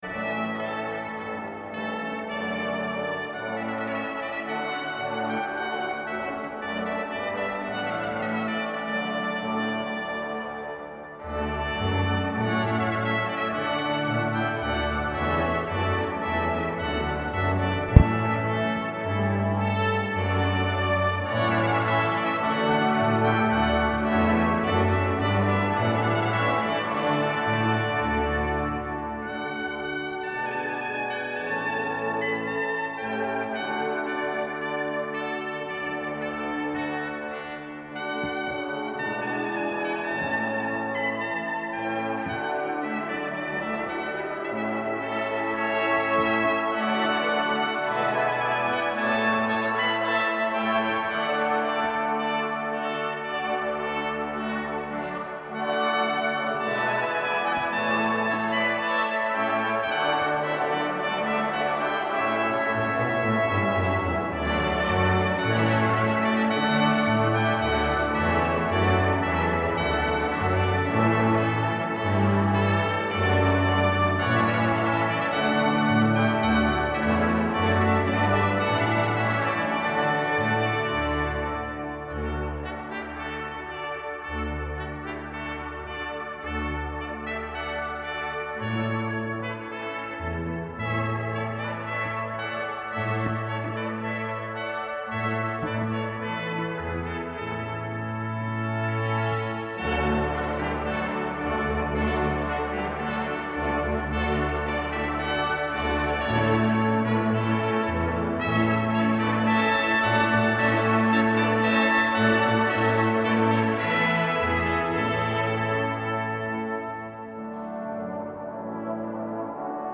第5日　8月12日（水）　　14時（日本時間21時）から、プラハ市内旧市街広場の聖ミクラーシュ教会で
石造りで天井の高い教会の響きはもうこの世のものとは思われず、吹き終わってからも天井あたりに8秒以上もただよう残響は、まさに「天の声」です。
「トランペット・ヴォランタリー」。　※頭が少し切れています